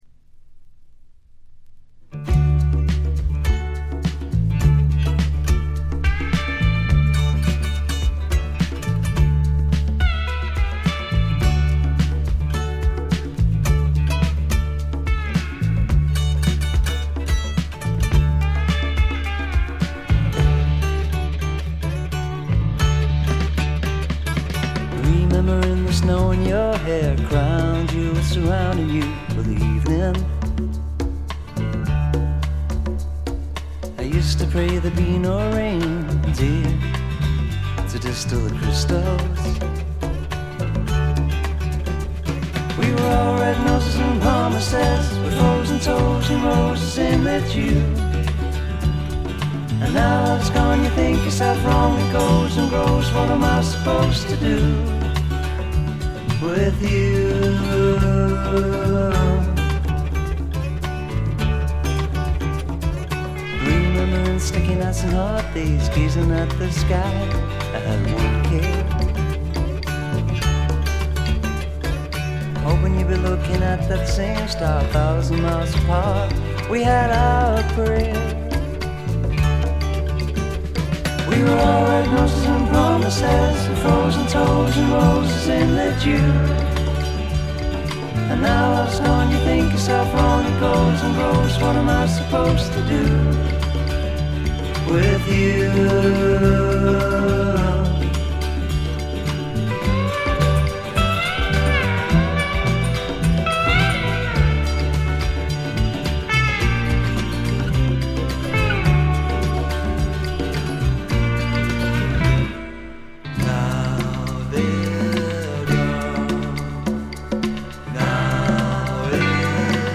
ごくわずかなノイズ感のみ。
内容は1971年という時代背景にあって典型的かつ最良の英国流フォーク・ロックです。
試聴曲は現品からの取り込み音源です。